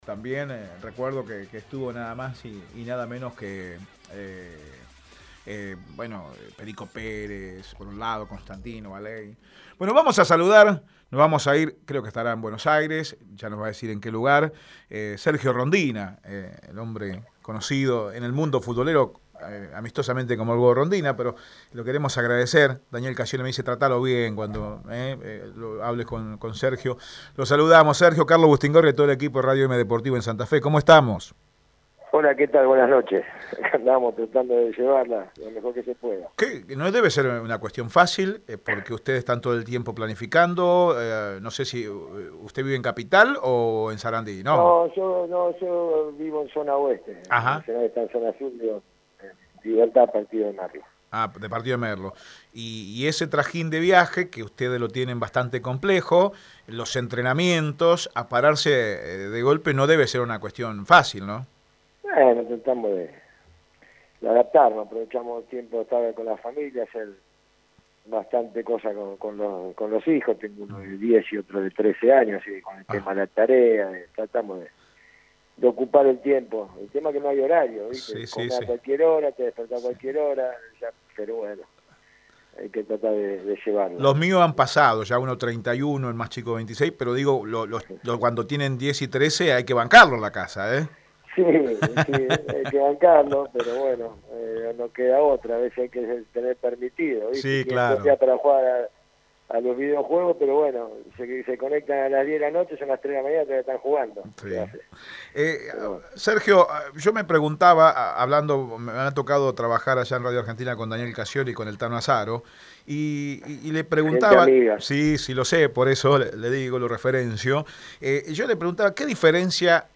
En exclusiva por Radio Eme deportivo habló Sergio Rondina, actual técnico de Arsenal, sobre como lleva la cuarentena y su situación en el elenco de Sarandí.